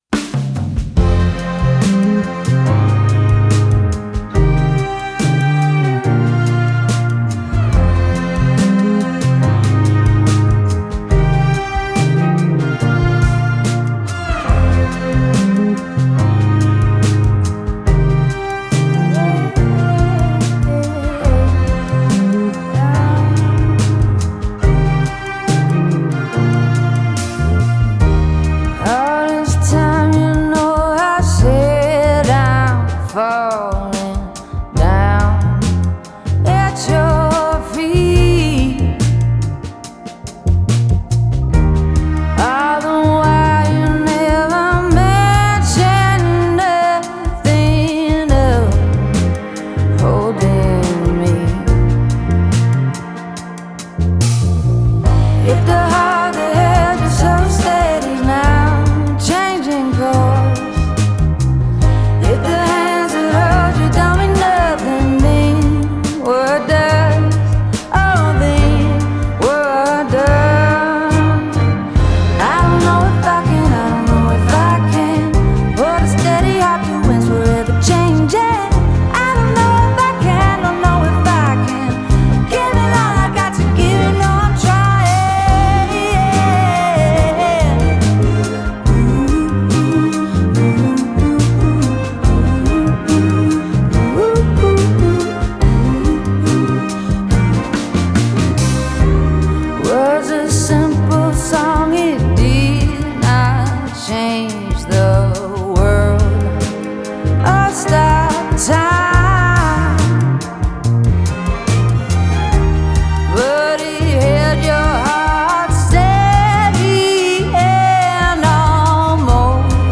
Genre: Indie Pop, Pop Rock,
Singer-Songwriter